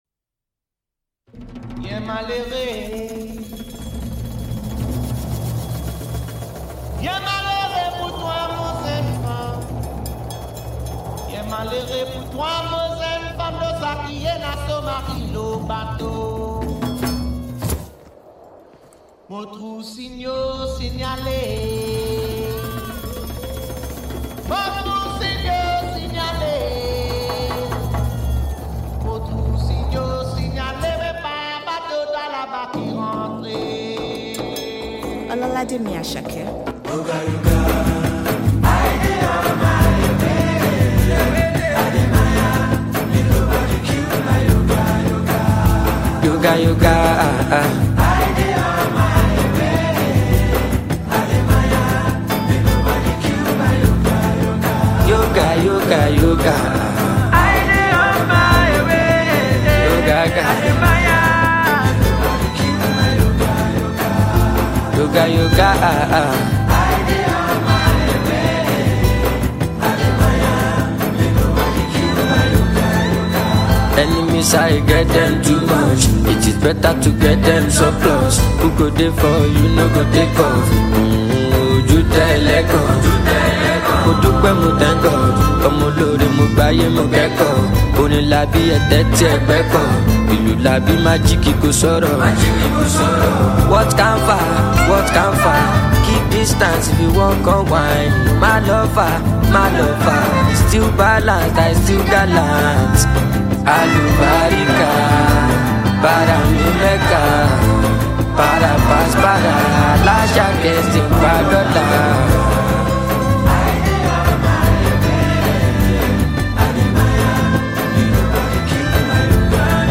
Top notch Nigerian singer